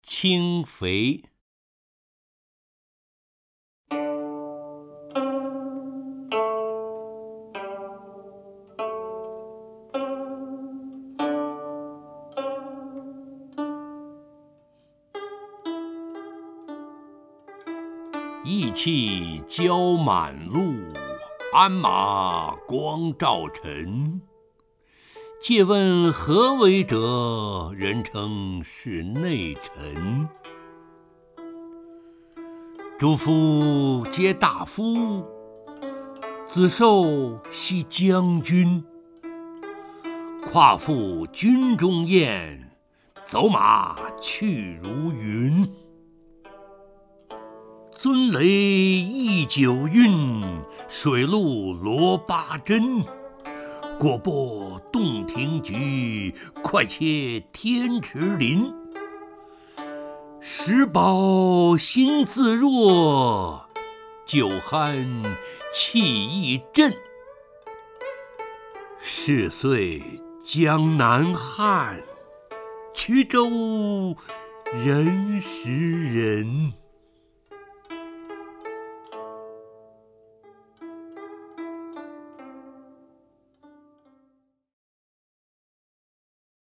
陈醇朗诵：《轻肥》(（唐）白居易)
名家朗诵欣赏 陈醇 目录